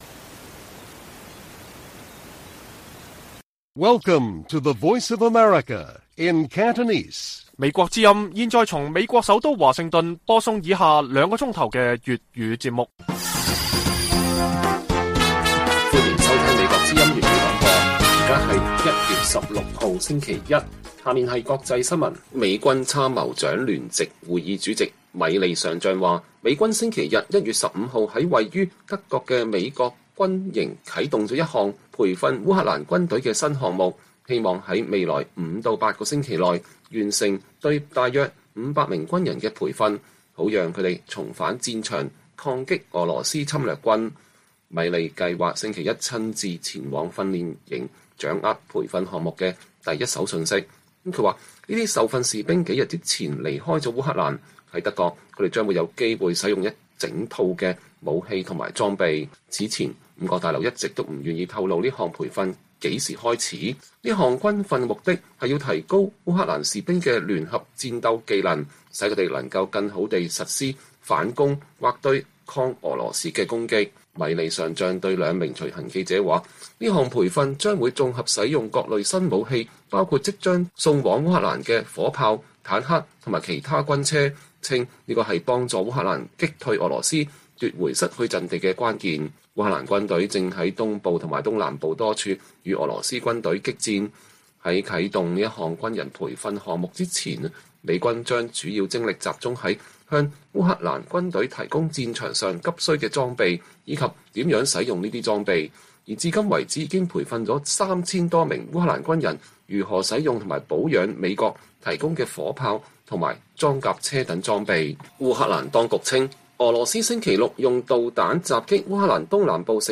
粵語新聞 晚上9-10點: 香港高鐵復運使用人面識別入閘 廣州東站售票機HK變XG引疑慮